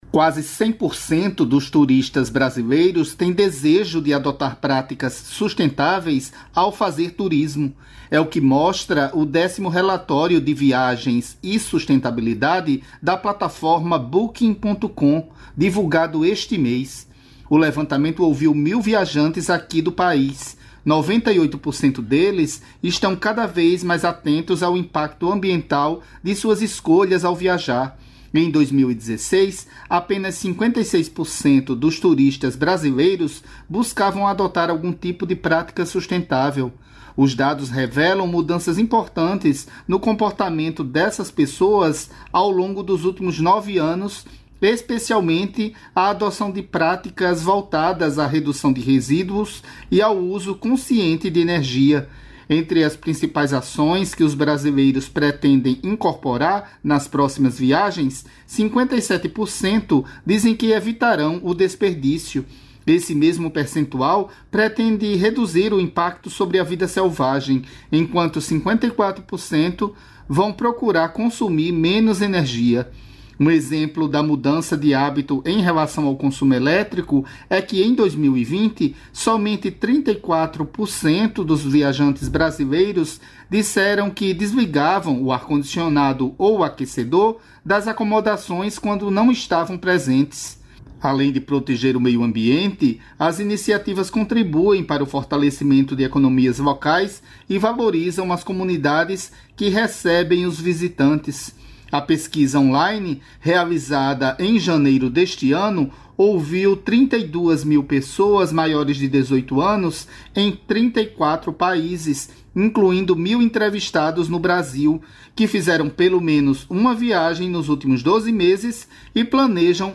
Rádio Agência